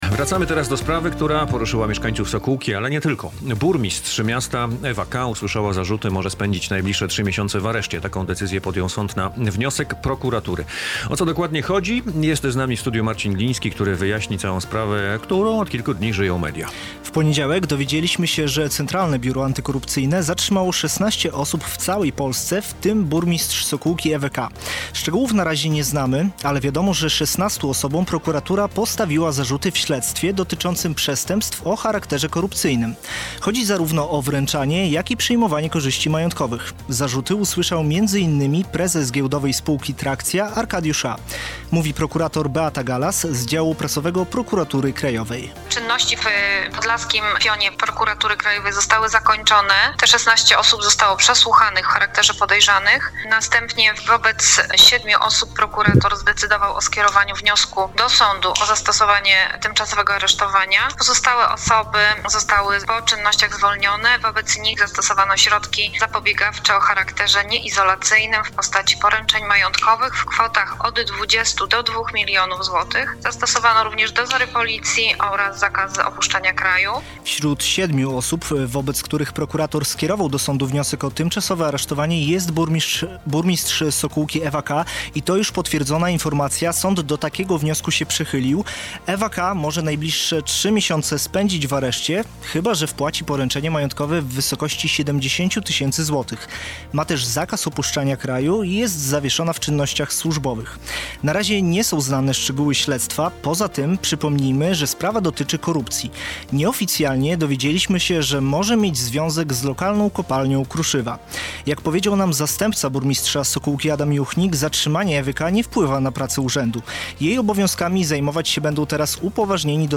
Jest decyzja o tymczasowym areszcie dla burmistrz Sokółki Ewy K. - relacja